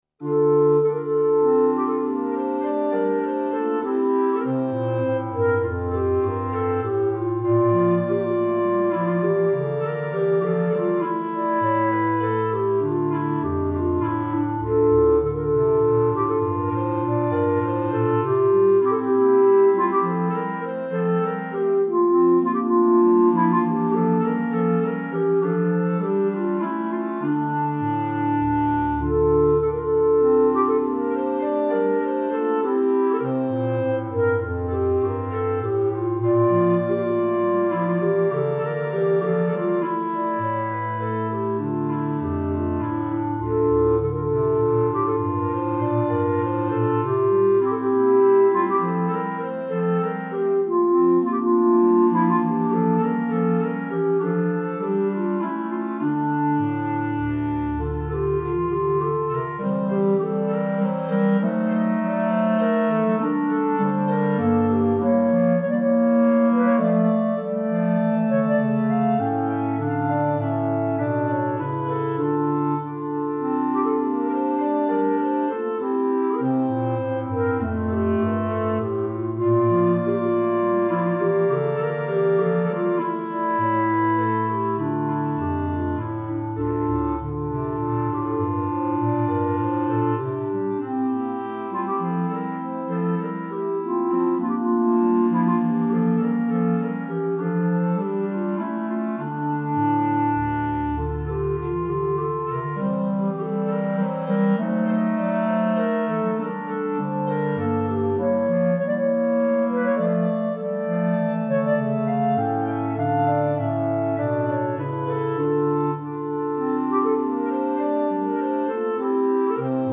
Instrumentation:Clarinet Quartet (includes Bass Cl)